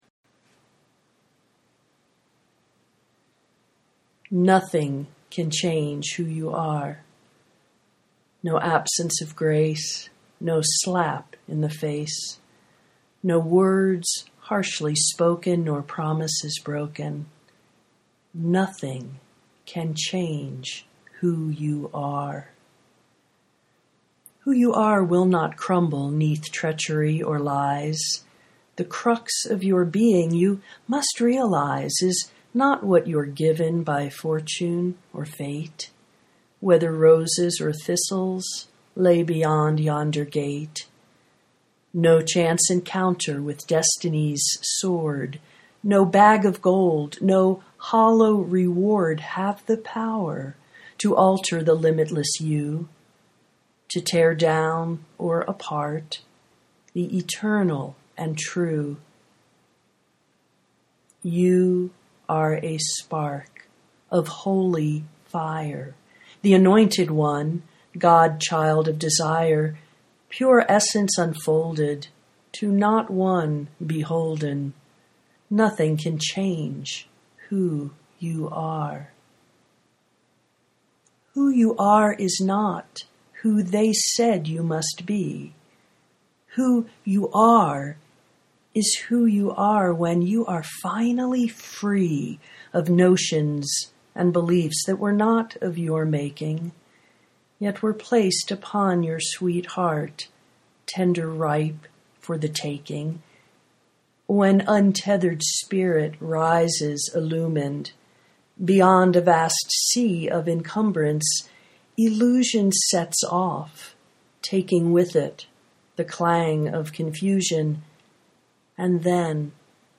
nothing can change who you are (audio poetry 2:55)